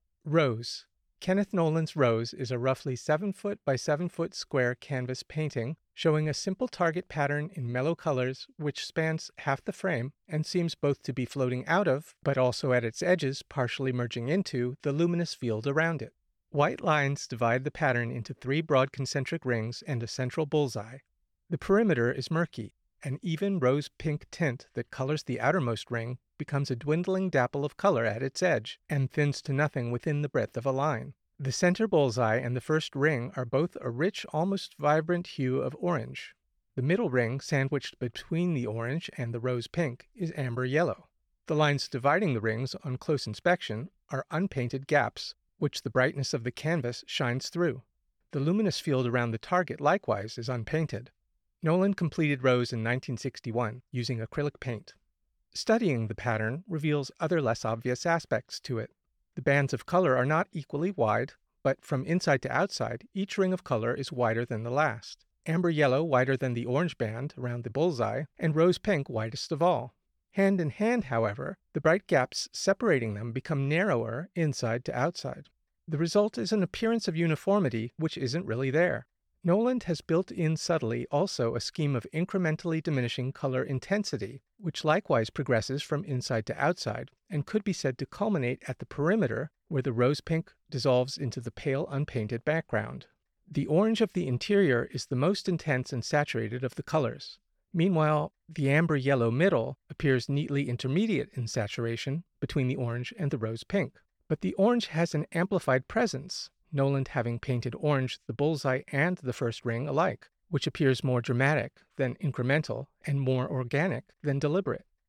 Audio Description (01:55)